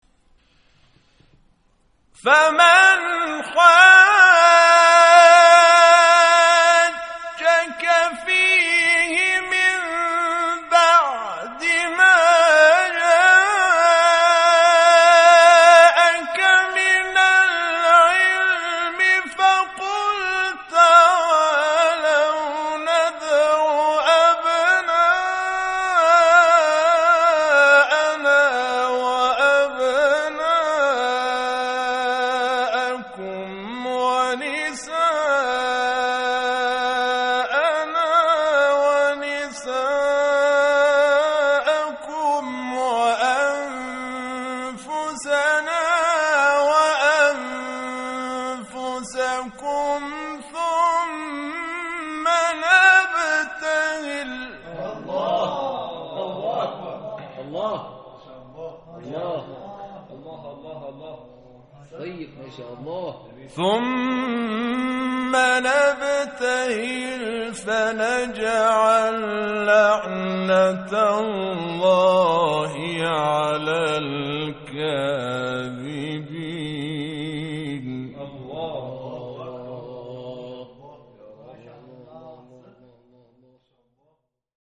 تلاوت قرآن، شب دوم جشن سال ۹۶